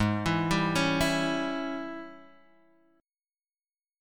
AbM7sus4#5 Chord